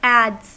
Ääntäminen
Ääntäminen US : IPA : [ædz] Haettu sana löytyi näillä lähdekielillä: englanti Käännöksiä ei löytynyt valitulle kohdekielelle.